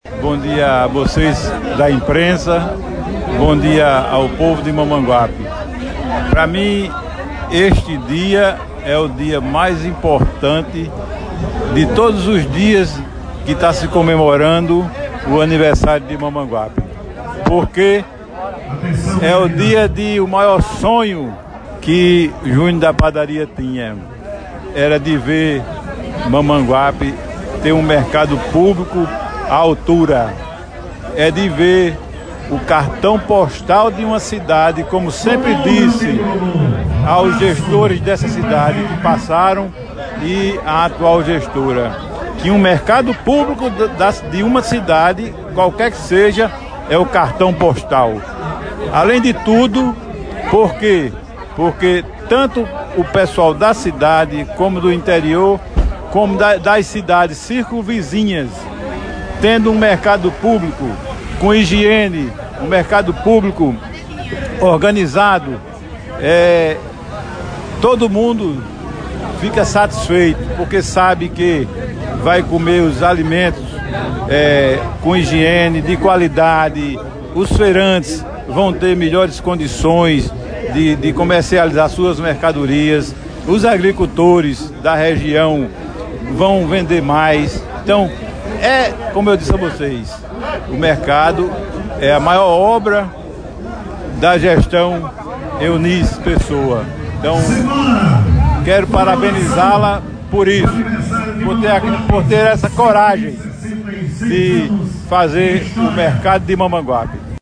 Vereador e presidente da Câmara de Mamanguape – Júnior da Padaria.